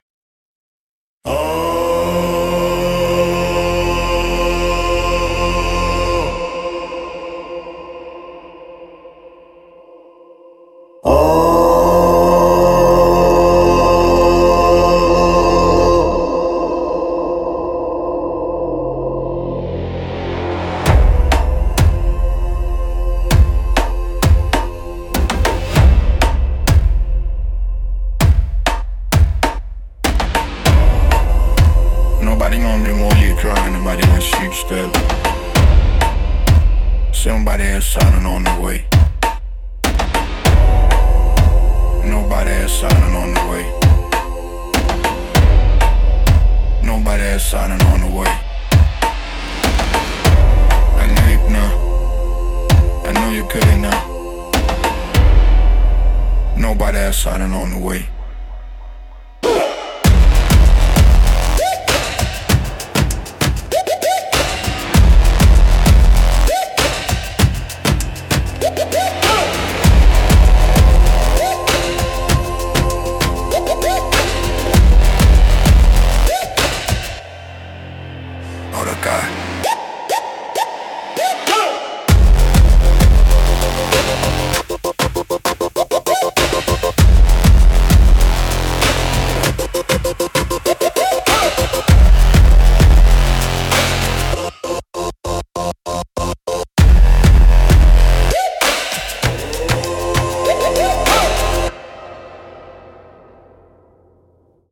Instrumental - The Drumming Circle -1.44